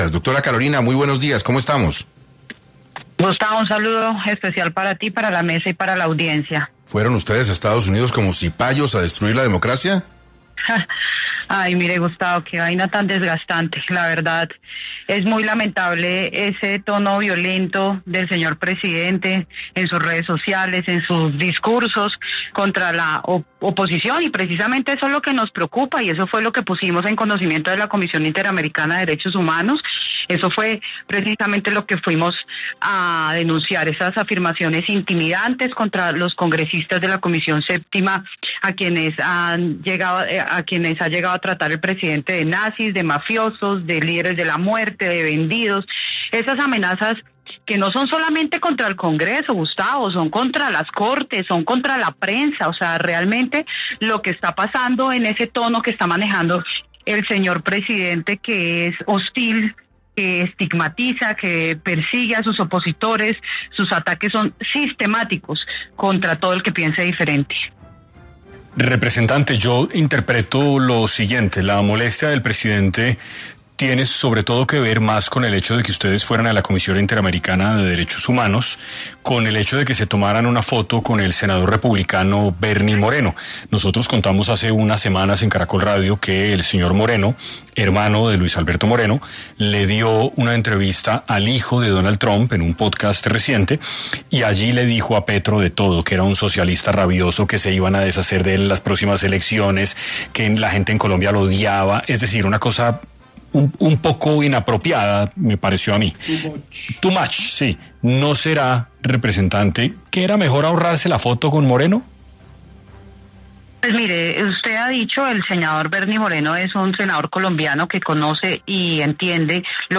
Carolina Arbeláez, Representante a la cámara, Habló para 6AM sobre las acusaciones del presidente Gustavo Petro por el viaje del grupo de congresistas colombianos a Estados Unidos